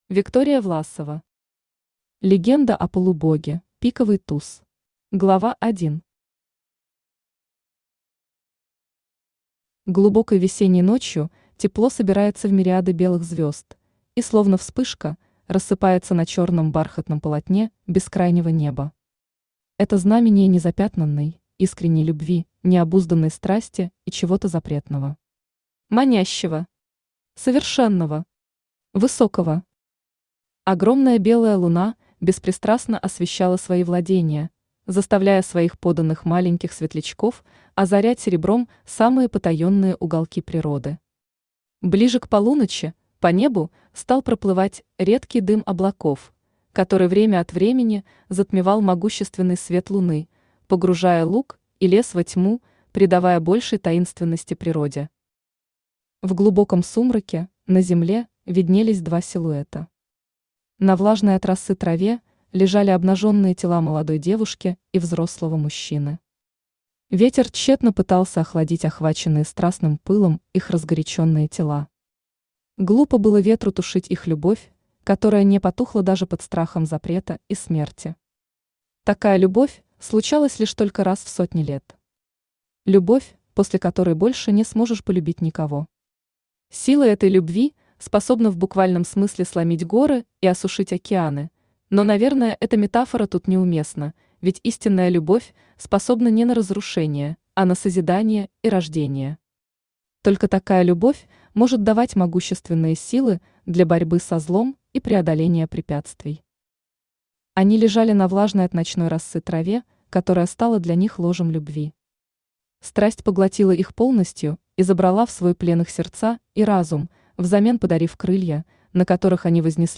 Аудиокнига Легенда о полубоге: пиковый туз | Библиотека аудиокниг
Aудиокнига Легенда о полубоге: пиковый туз Автор Виктория Сергеевна Власова Читает аудиокнигу Авточтец ЛитРес.